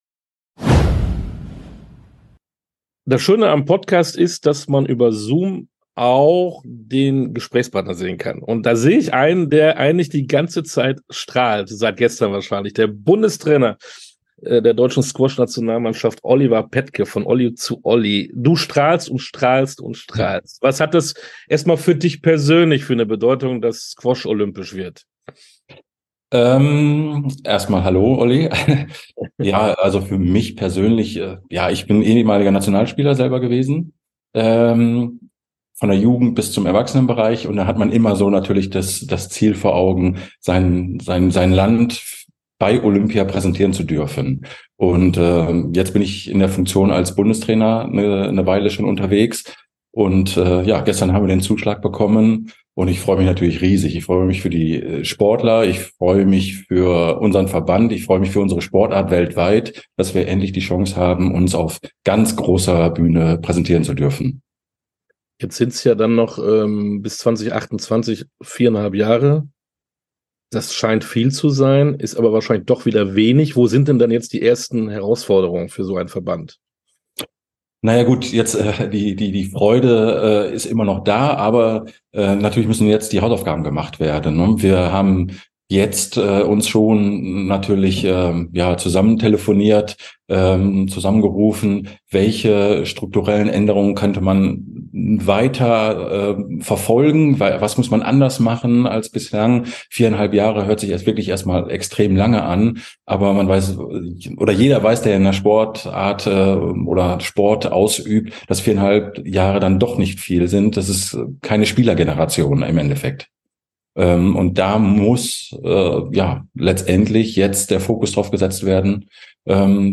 Interviews in voller Länge Podcast